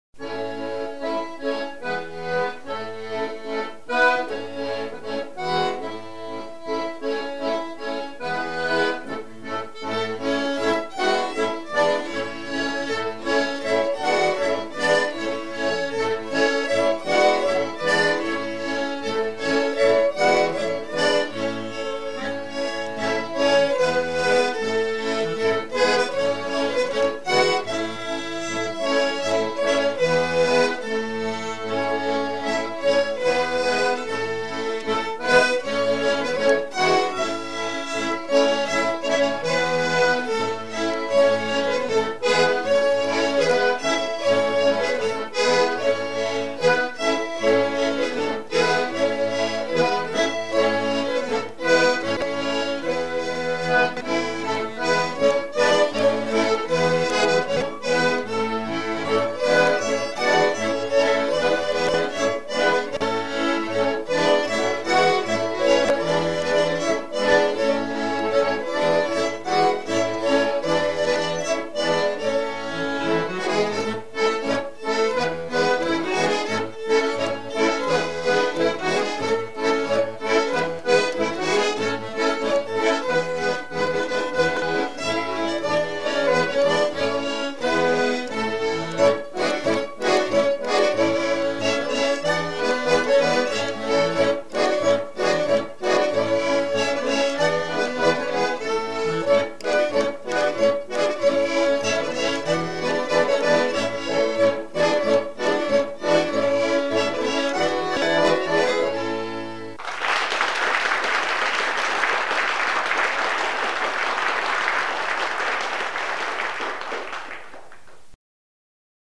Genre énumérative
Pièce musicale éditée